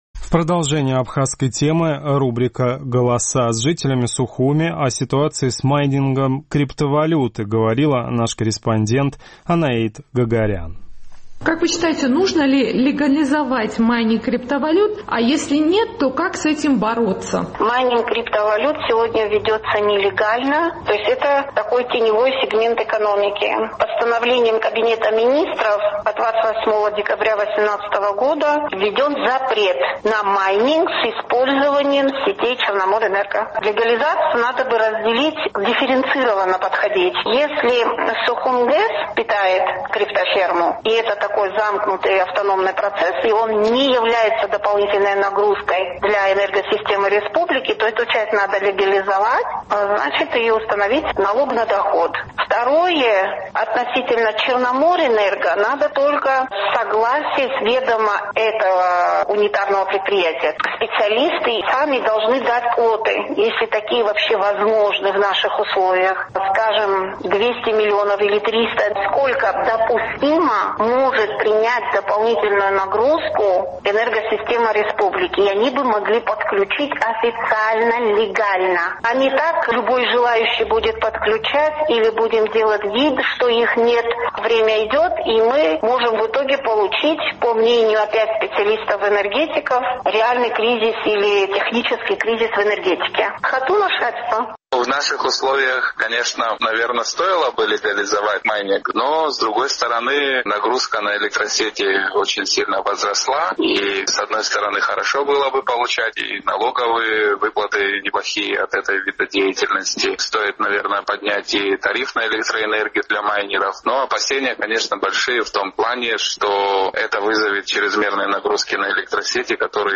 Мы решили выяснить у местных жителей, что делать с этим бизнесом – легализовать или бороться, и если второе – то как именно? Наш традиционный сухумский опрос.